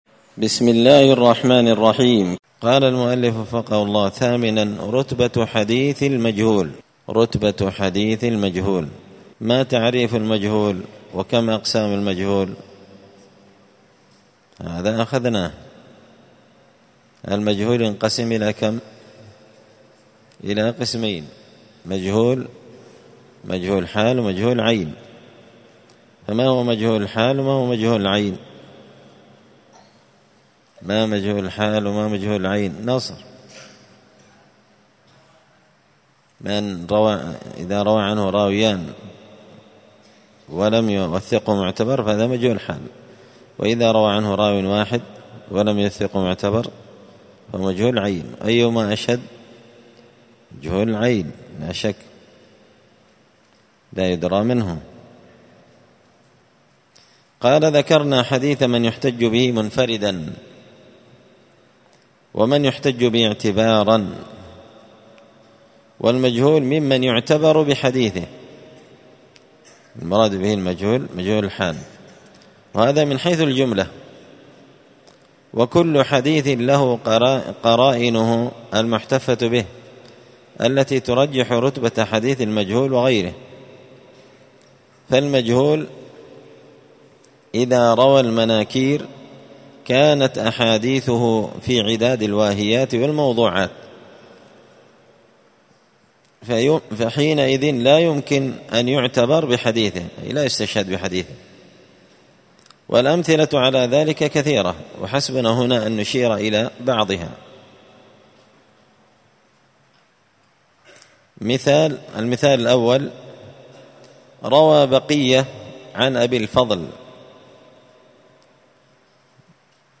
*الدرس السابع عشر (17) رتبة حديث المجهول*